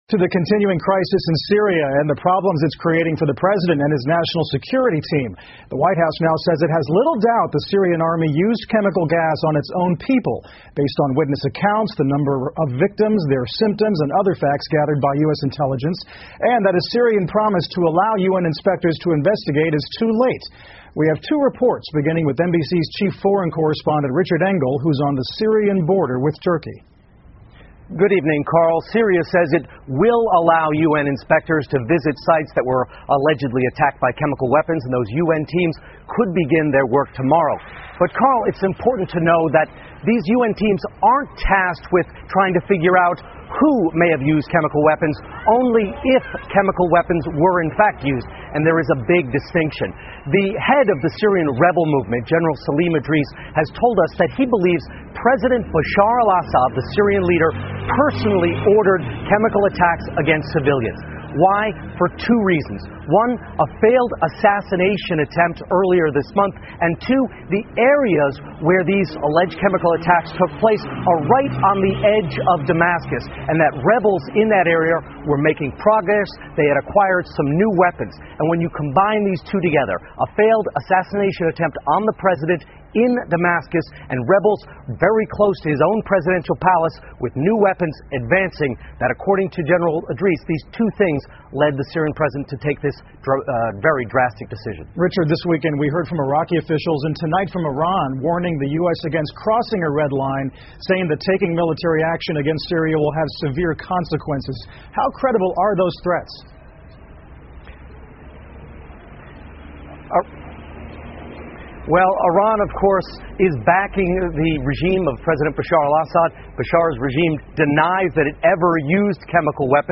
NBC晚间新闻 总统计划参与调查叙利亚化学战证据 听力文件下载—在线英语听力室